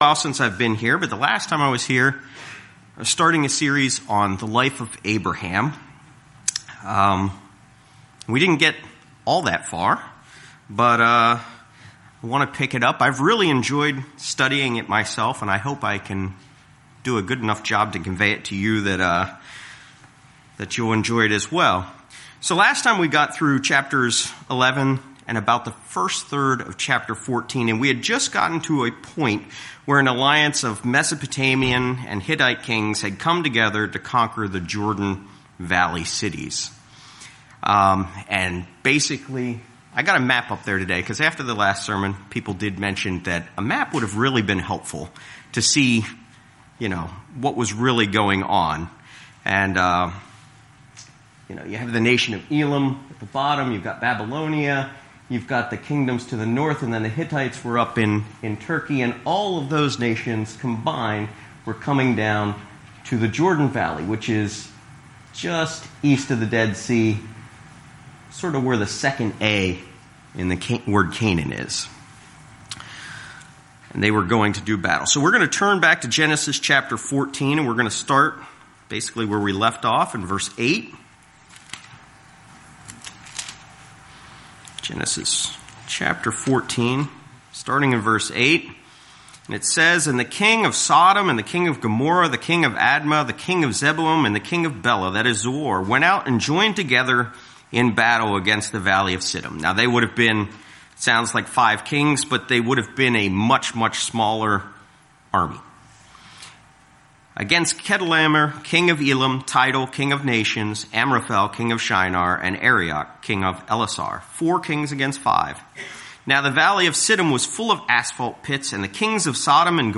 This is the second sermon in a series reviewing the life of Abraham. This sermon reviews Abram’s interaction with Melchizedek, God’s covenants with Abraham, and having Ismael through Hagar.
Given in Lehigh Valley, PA